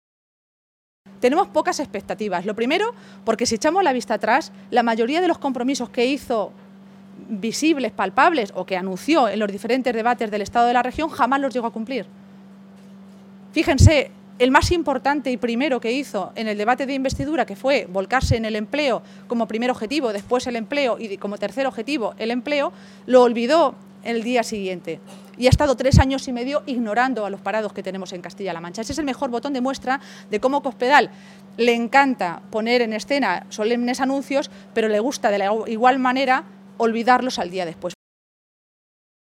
Maestre se pronunciaba de esta manera esta tarde, en Toledo, en una comparecencia previa a la reunión de la ejecutiva regional socialista en la que, según ha apuntado, se va a analizar el calendario de todos los procesos internos que debe afrontar a partir de ahora los socialistas.